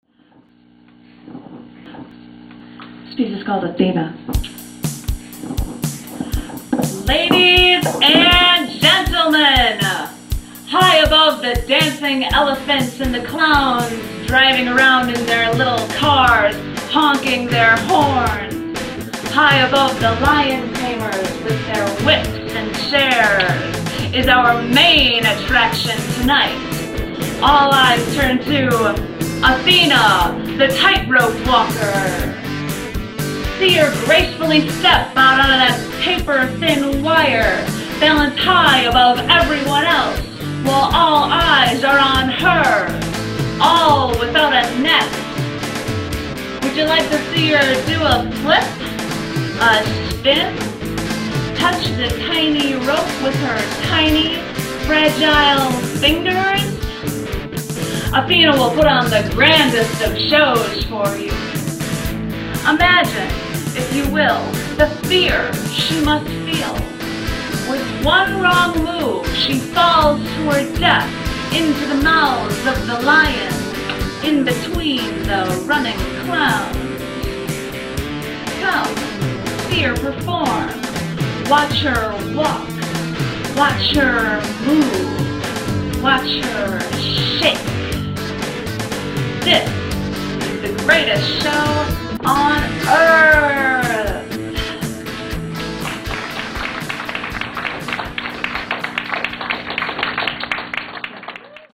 designed for the Chicago performance art event
(LIVE track, recorded 04/01/05)
• Boss DR-550mkII drum box
• Vox AD15VT guitar amplifier
• Fender American Telecaster